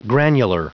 Prononciation du mot granular en anglais (fichier audio)
Prononciation du mot : granular